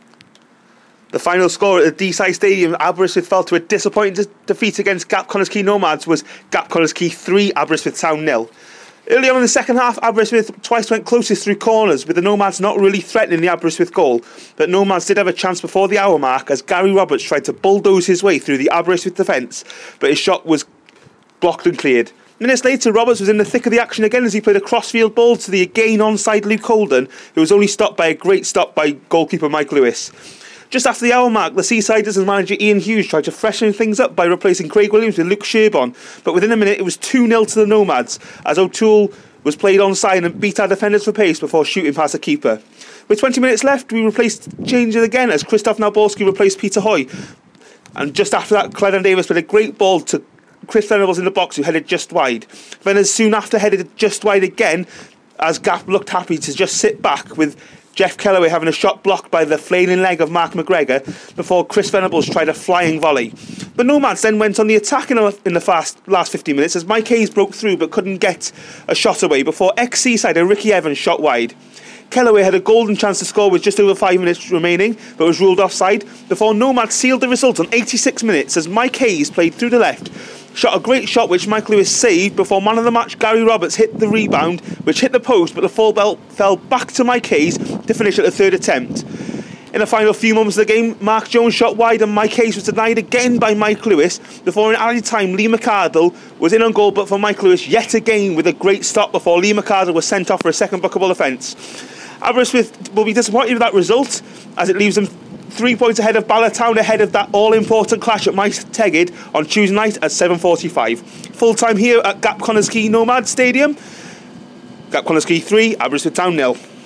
second half report